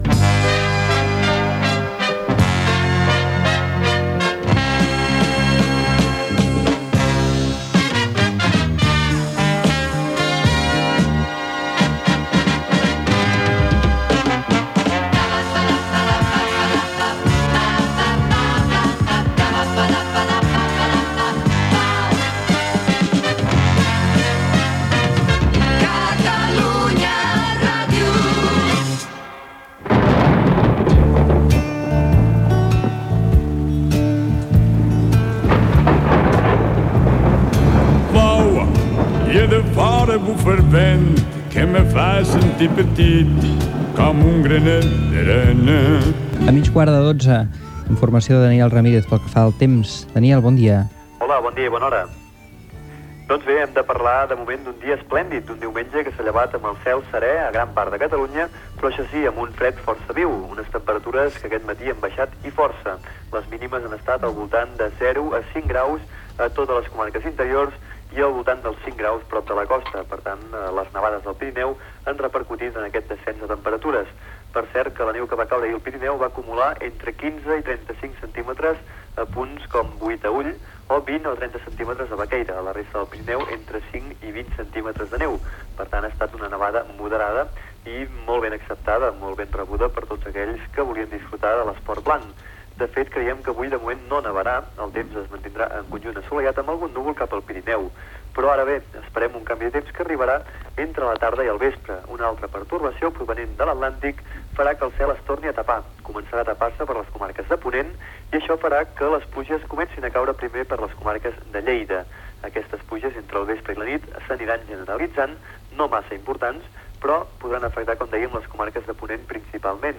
Indicatiu de l'emissora, hora, informació del temps, informació castellera, publicitat
Entreteniment
FM